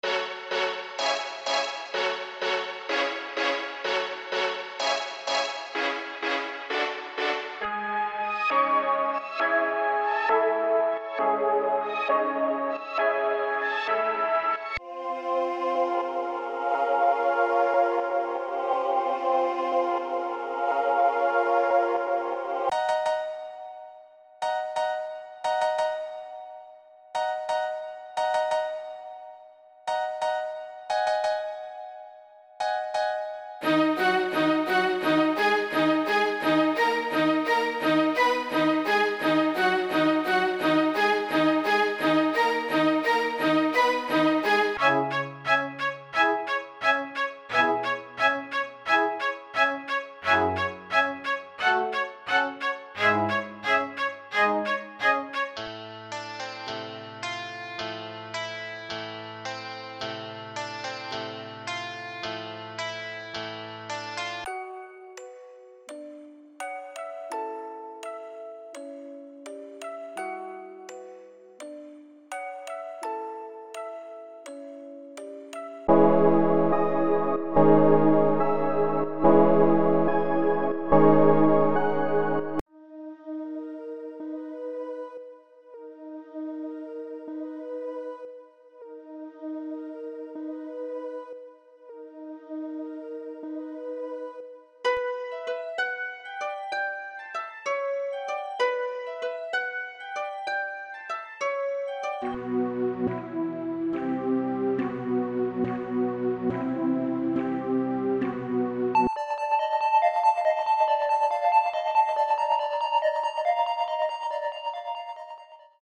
• 16 Melody Loops